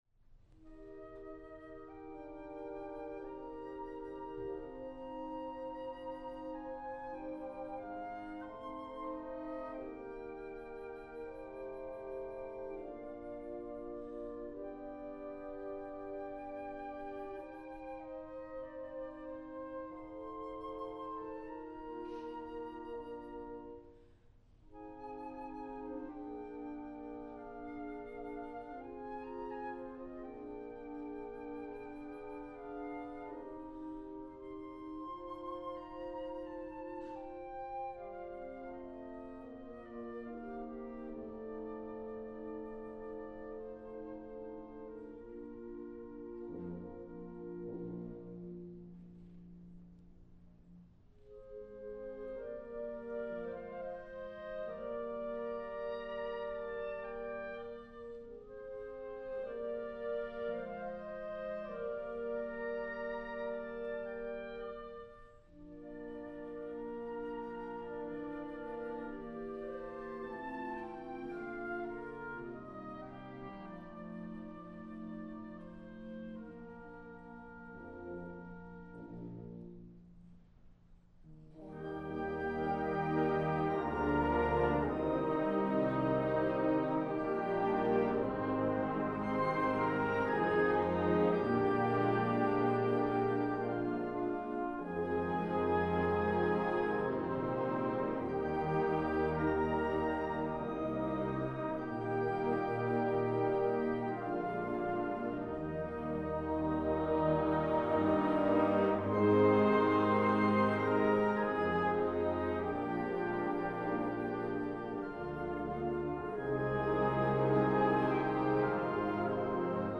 Genre: Band
Piccolo
Oboe
Euphonium
Tuba
Harp or Piano (opt.)